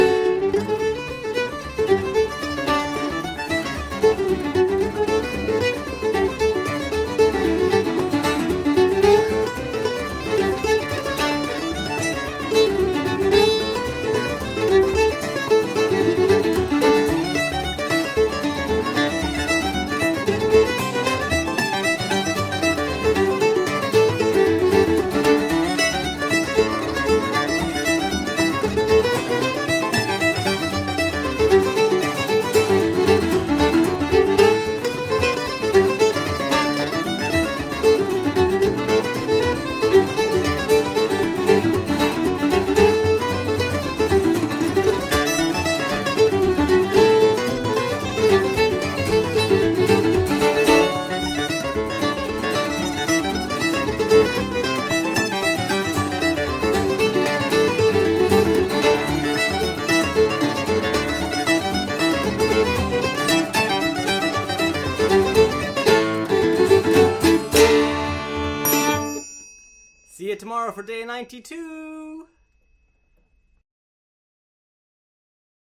Sporting Paddy reel set